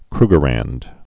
(krgə-rănd, -ränd)